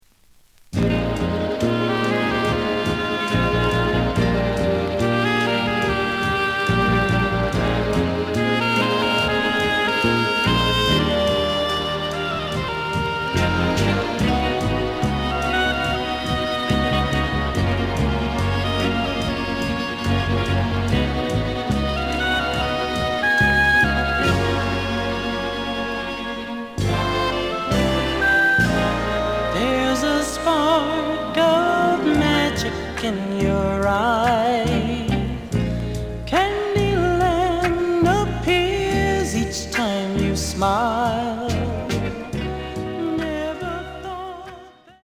The audio sample is recorded from the actual item.
●Genre: Soul, 70's Soul
Some click noise on A side due to scratches.